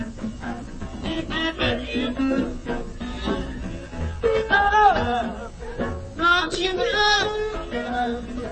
blues_blues.00004.mp3